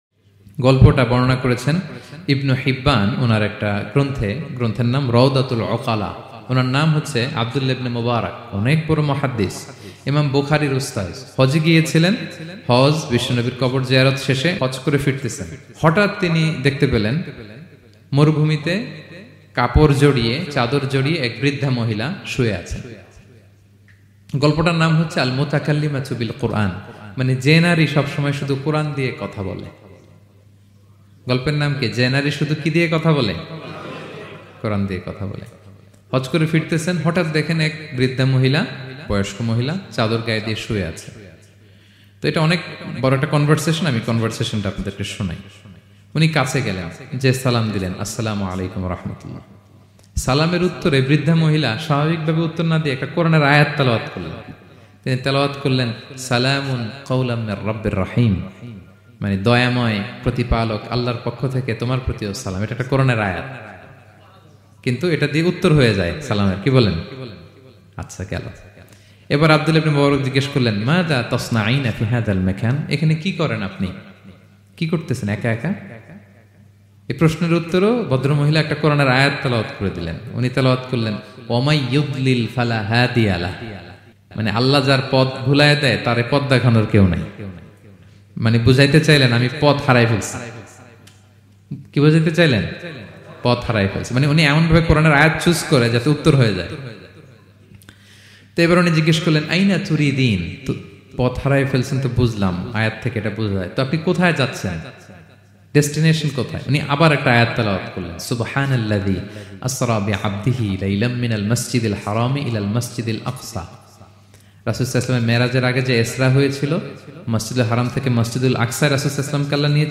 Motivation waz